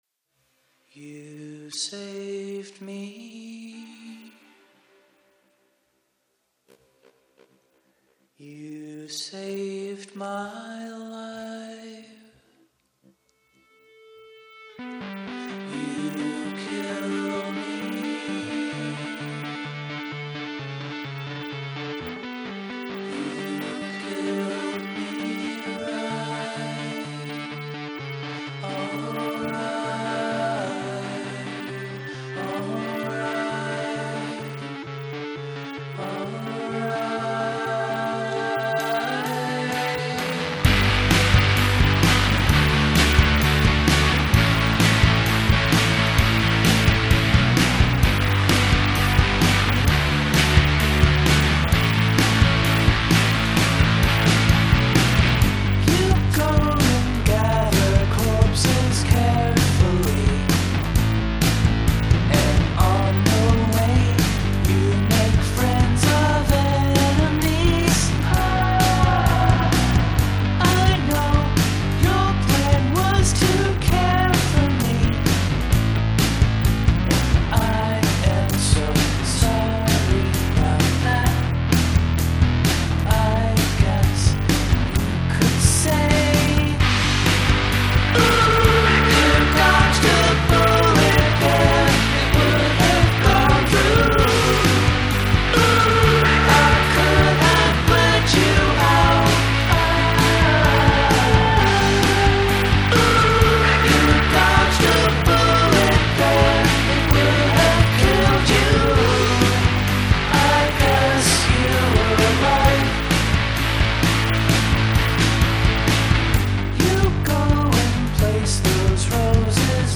First, the crunchy rock song: